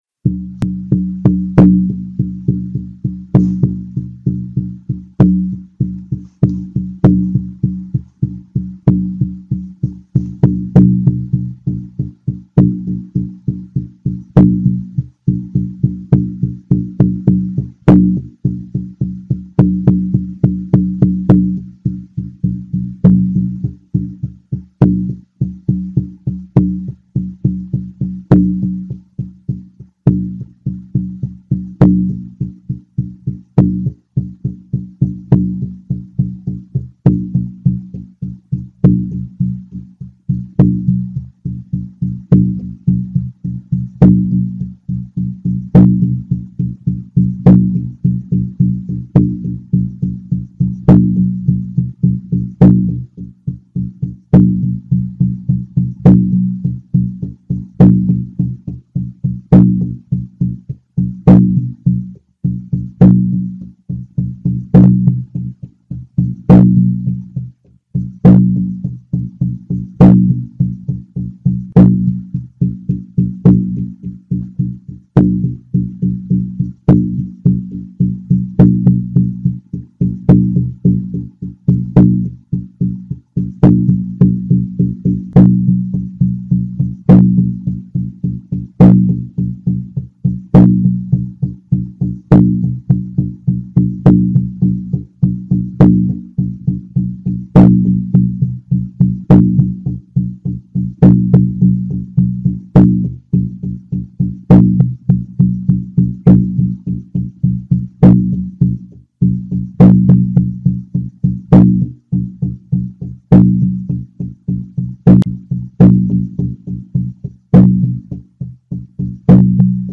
A Drumming Track for your Shamanic Journeying. 10 min with return beat